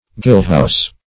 Search Result for " gillhouse" : The Collaborative International Dictionary of English v.0.48: Gillhouse \Gill"house`\, n. A shop where gill is sold.